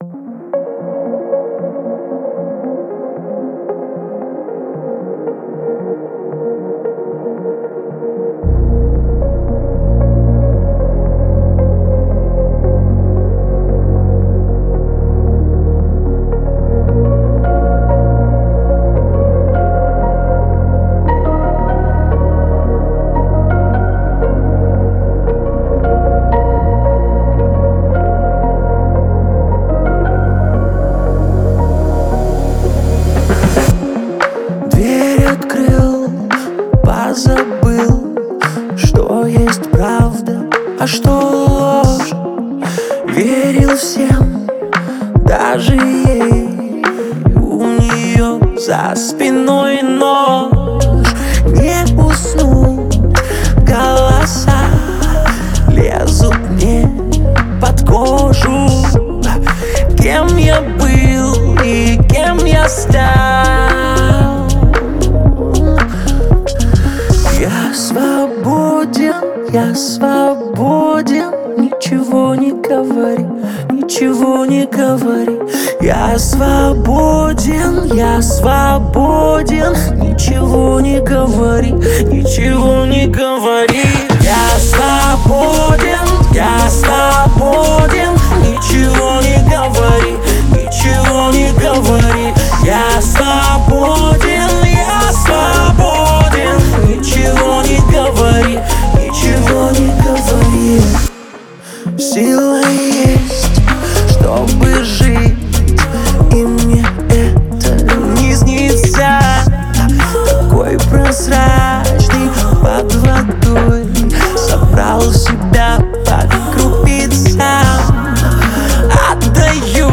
мощная поп-баллада